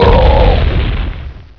BANG.WAV